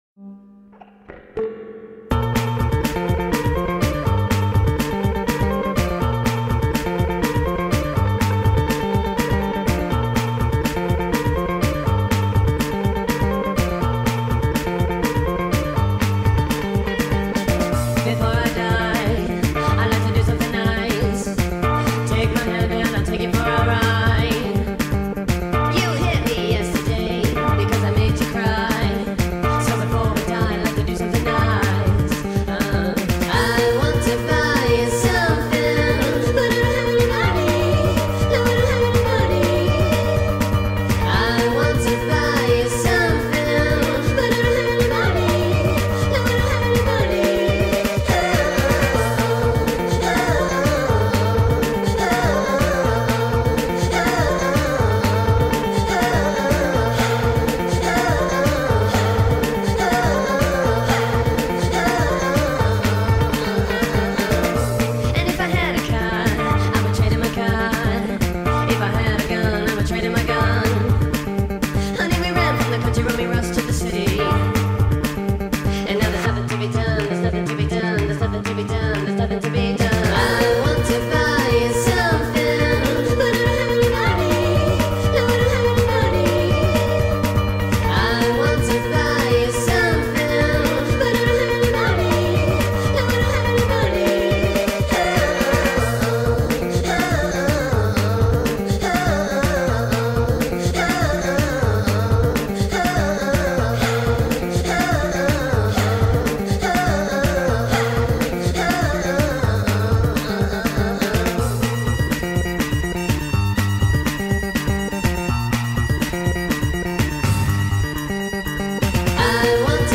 sped up remix
TikTok remix